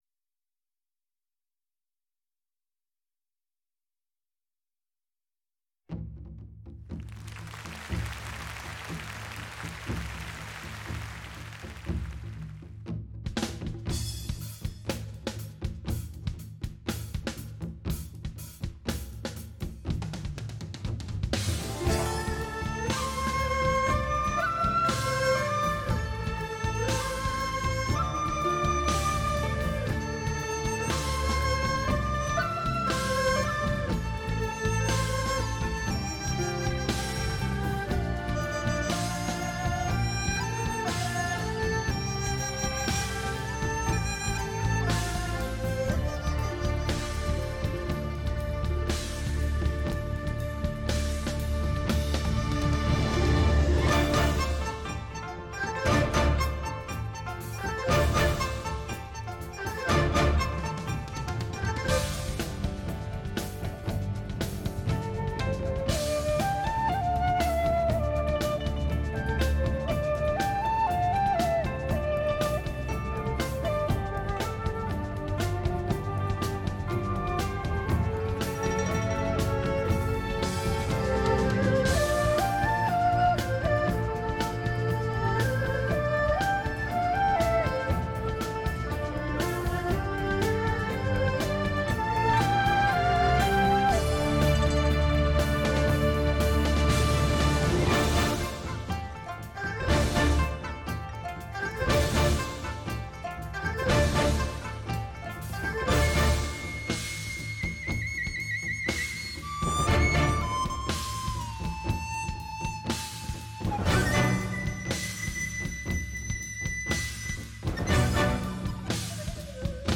看12个妙龄女子操持着中国古老乐器的唯美，
听她们指尖滑出的行云流水般的乐曲，